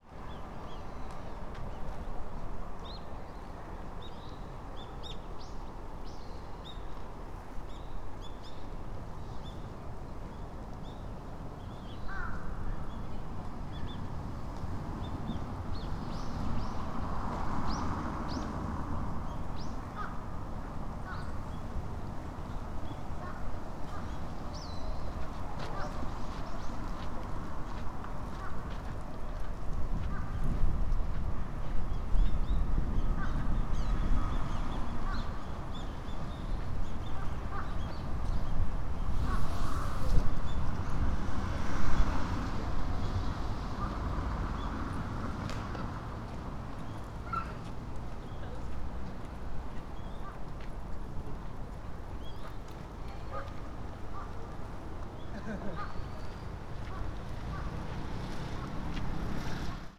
Fukushima Soundscape: Mt. Shinobu
In the occasional light snowfall, some children and also some adults were playing at Mt. Shinobu Park. ♦ The cawing of crows and songs of birds were heard as usual. ♦ The cars visiting Gokoku Shrine ceaselessly passed through the street next to the park.